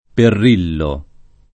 [ perr & llo ]